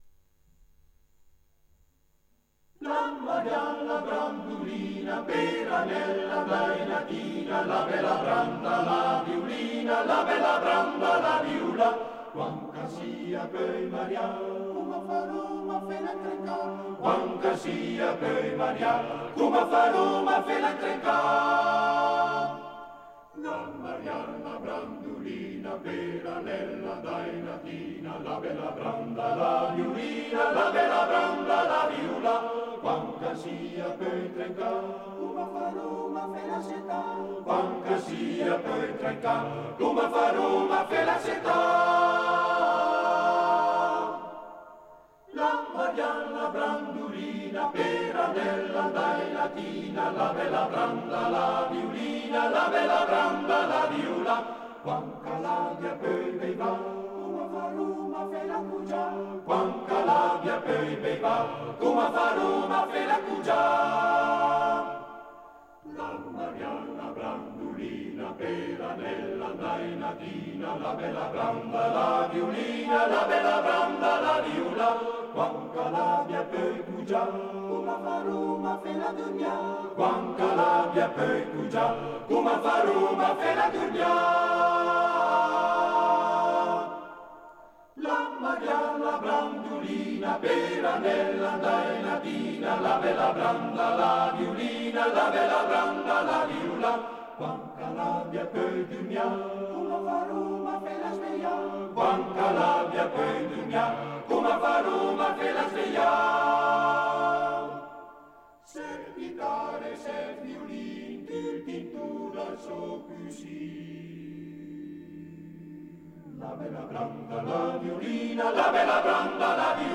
Arrangiatore: Dionisi, Renato
Esecutore: Coro della SAT